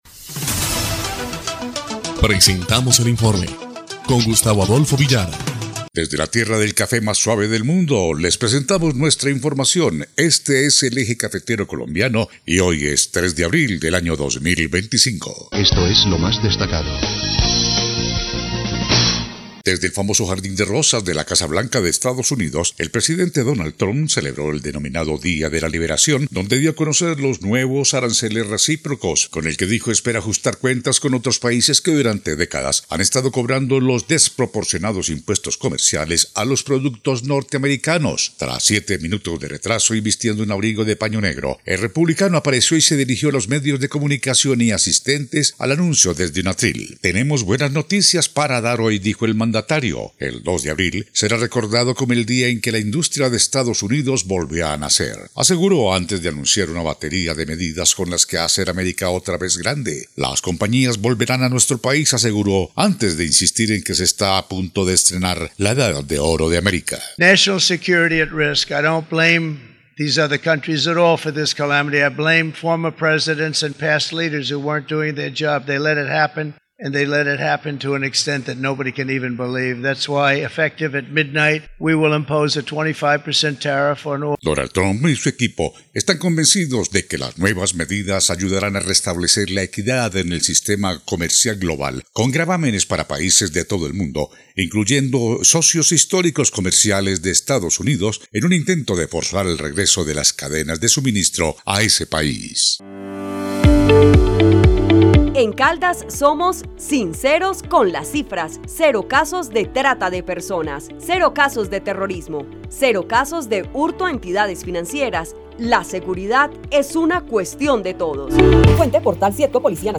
EL INFORME 1° Clip de Noticias del 3 de abril de 2025